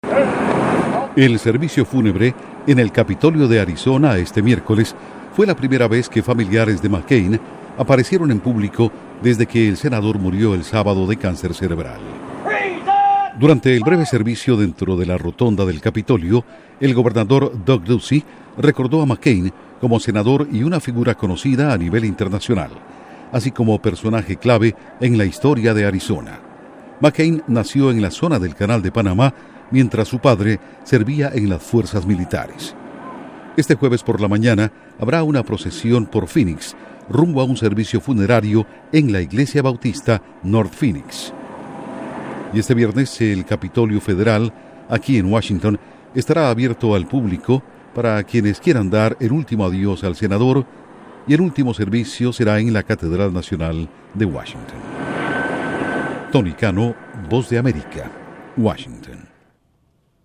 Intro: Los restos de John McCain yacen en el Capitolio de Arizona. Informa desde la Voz de América en Washington